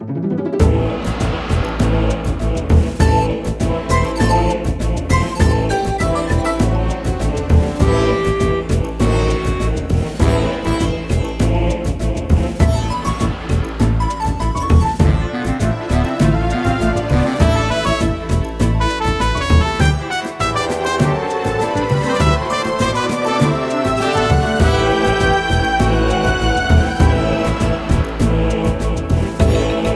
Music theme